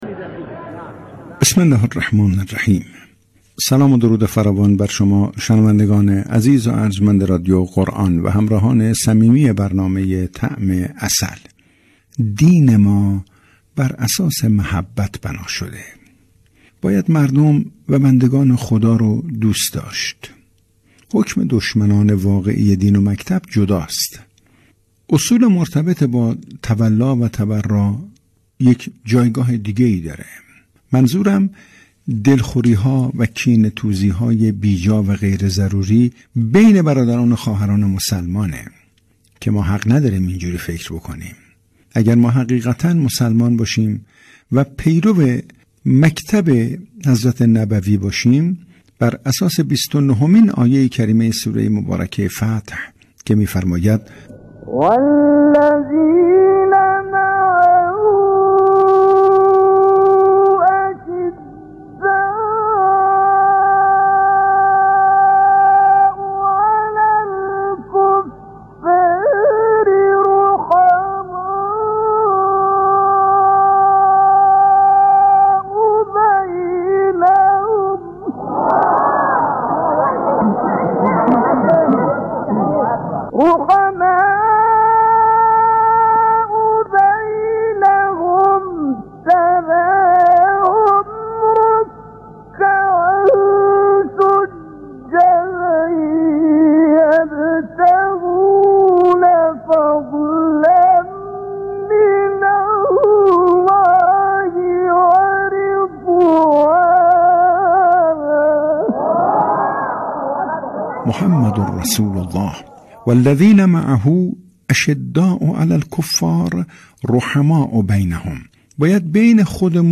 مجله صبحگاهی زنده «تسنیم» رادیو قرآن در ایام دهه ولایت و امامت با آیتم‌های جذاب و پخش اناشید و همخوانی‌ها در وصف مولای متقیان حضرت علی (ع) و گزارش از حال و هوای غدیریه محلات به روی آنتن می‌رود.